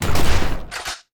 Sfx Icegun Shoot Sound Effect
sfx-icegun-shoot-1.mp3